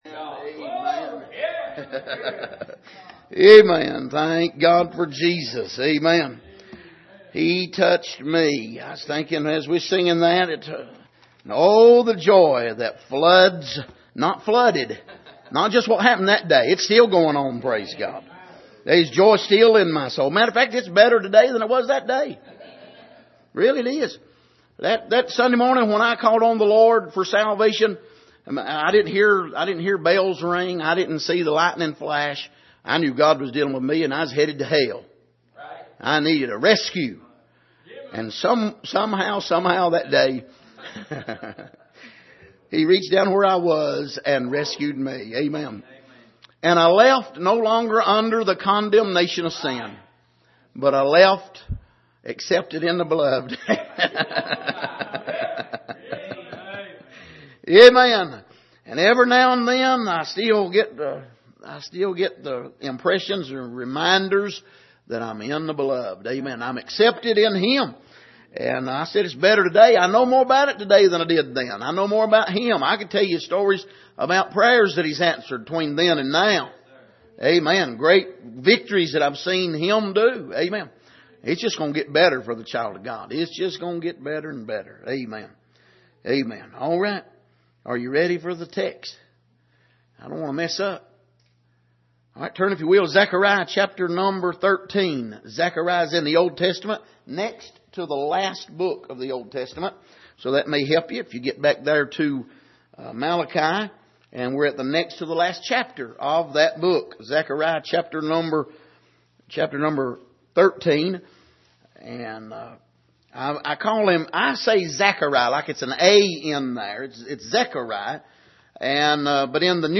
Passage: Zechariah 13:7 Service: Sunday Morning